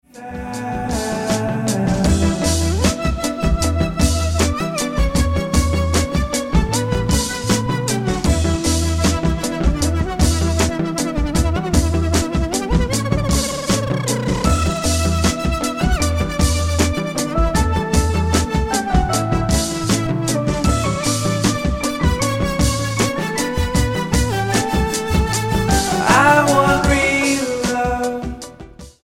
STYLE: Ambient/Meditational